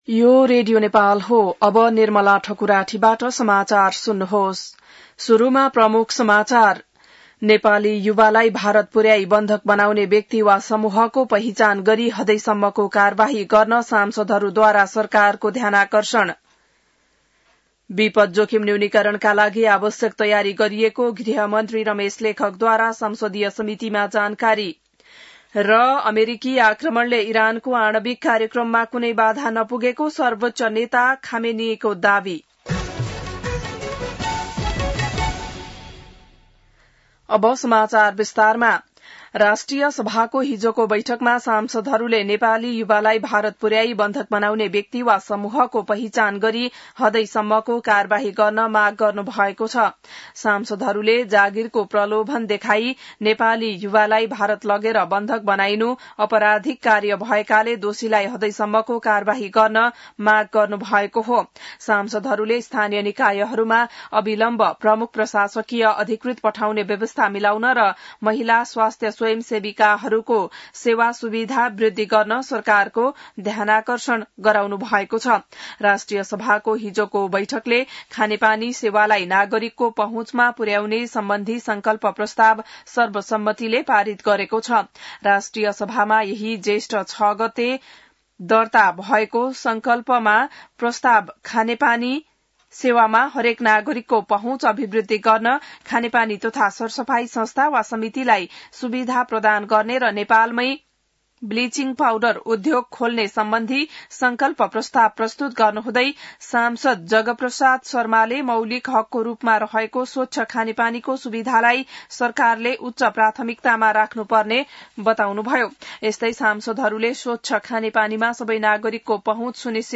बिहान ९ बजेको नेपाली समाचार : १३ असार , २०८२